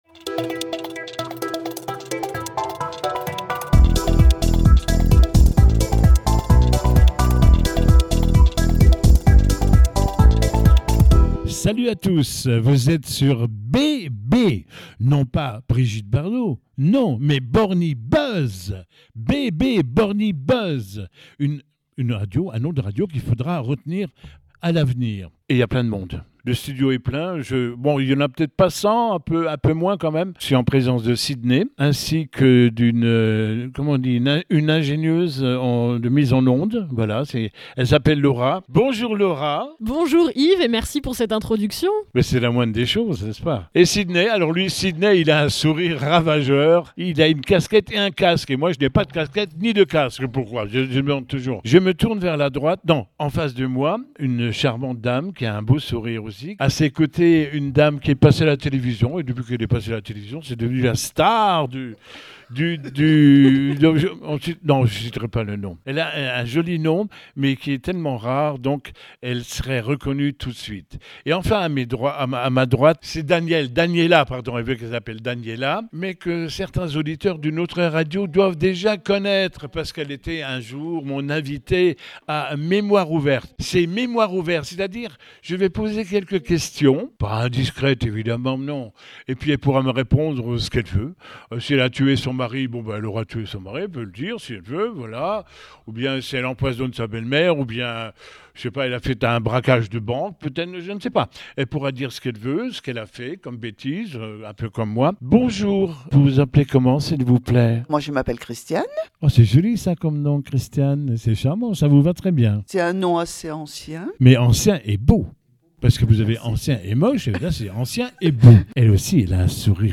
Récit de vie
réalisé à la résidence Sainte-Croix